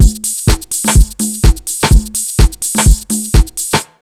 126BEAT1 2-L.wav